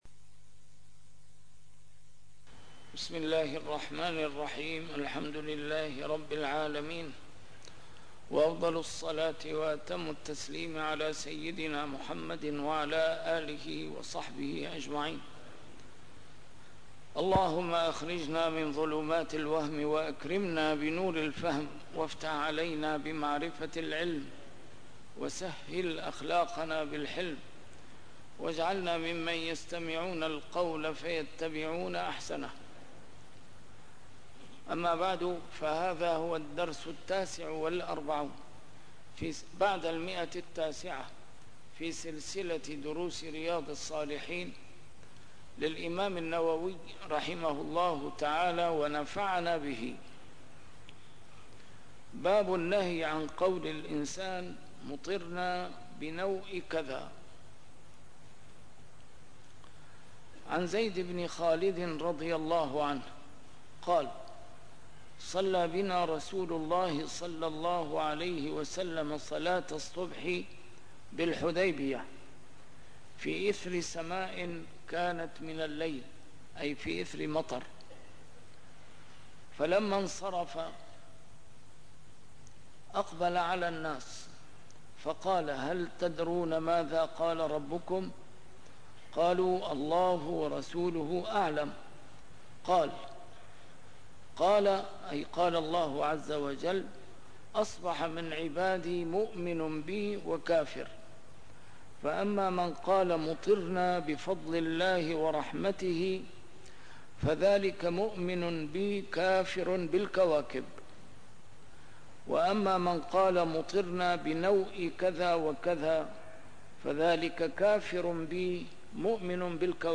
A MARTYR SCHOLAR: IMAM MUHAMMAD SAEED RAMADAN AL-BOUTI - الدروس العلمية - شرح كتاب رياض الصالحين - 949- شرح رياض الصالحين: النهي عن قول مطرنا بنوء كذا - تحريم قوله لمسلم: يا كافر